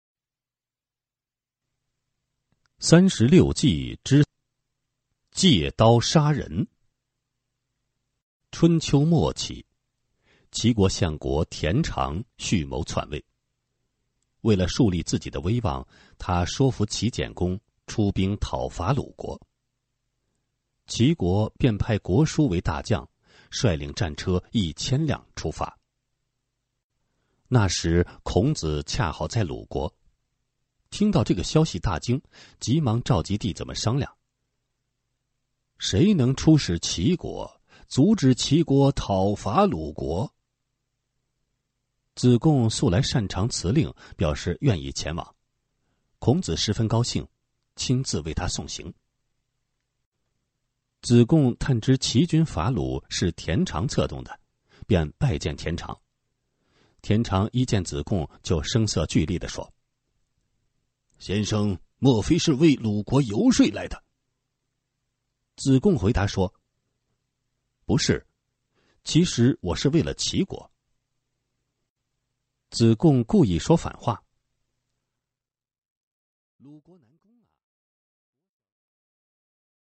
Audiobook - 三十六计之借刀杀人